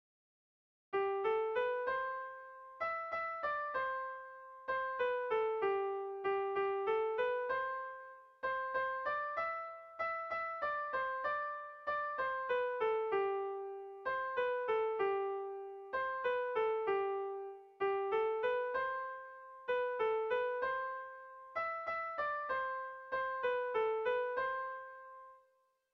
Irrizkoa
ABD.